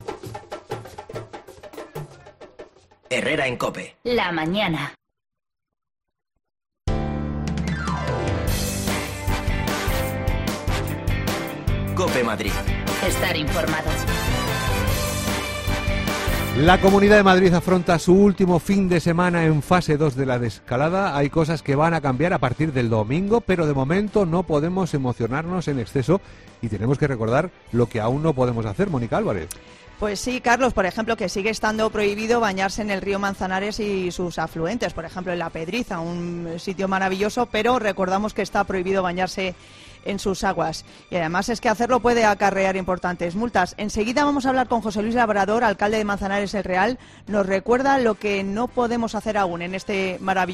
Hablamos con el alcalde de Manzanares El Real quién nos recuerda que sigue prohibido, entre otras cosas, el baño en La Pedriza
Las desconexiones locales de Madrid son espacios de 10 minutos de duración que se emiten en COPE, de lunes a viernes.